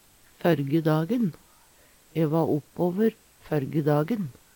førge dagen - Numedalsmål (en-US)
Tilleggsopplysningar ein seier og "førje"